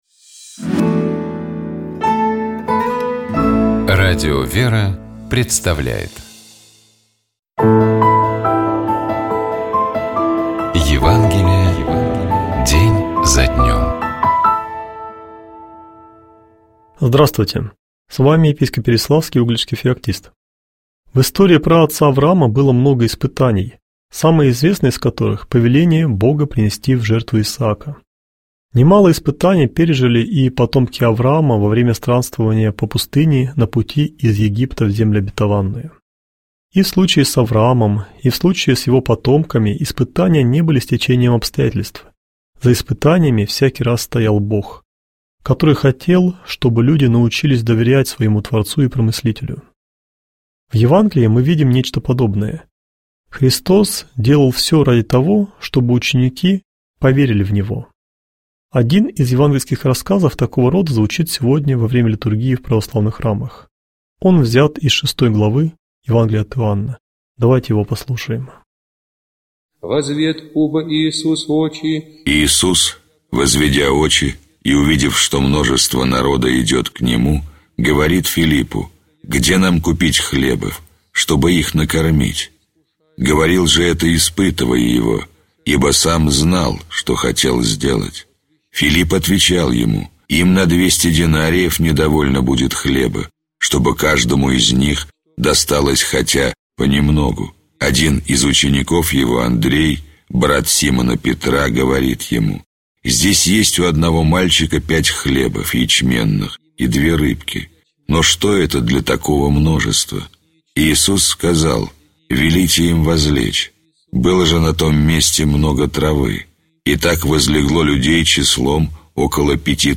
епископ Феоктист ИгумновЧитает и комментирует епископ Переславский и Угличский Феоктист